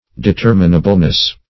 Search Result for " determinableness" : The Collaborative International Dictionary of English v.0.48: Determinableness \De*ter"mi*na*ble*ness\, n. Capability of being determined; determinability.